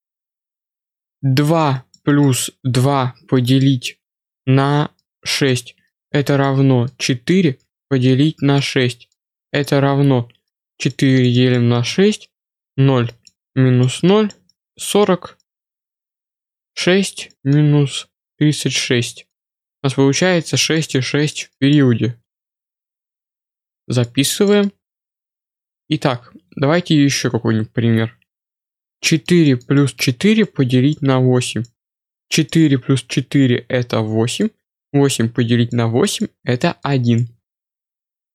Как убрать щелчки от клавиш мыши с записи?